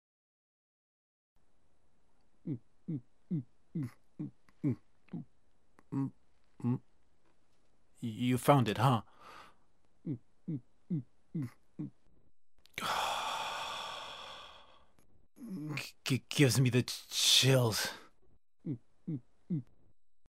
VOICE / CUTSCENES
This also contains Jasper's Dialogue.
SH4-Forest-Cutscene-Jasper-011.mp3